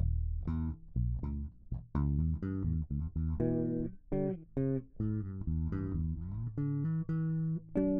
Quick recordings, rusty playing of 6 strings Harley Benton bass, passive mode.